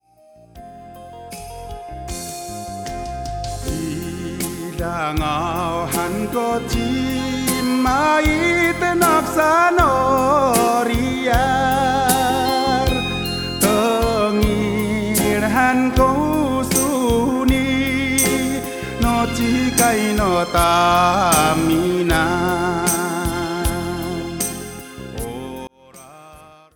an ‘amis popular song from the mid-1980s
making a nearly identical instrumental backing track